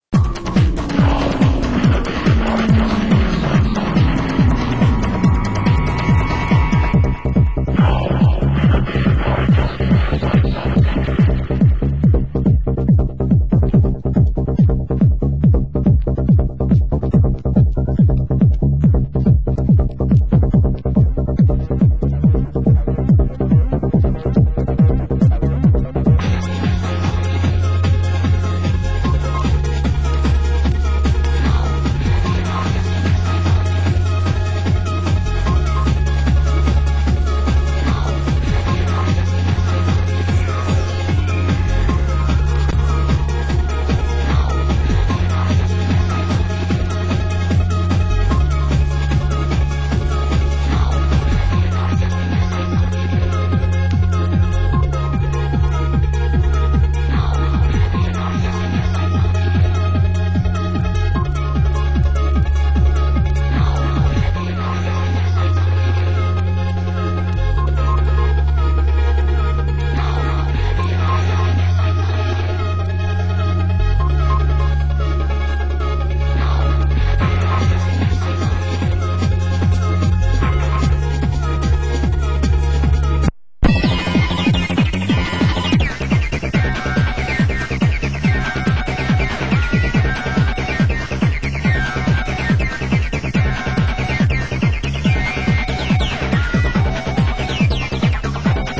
Genre: Trance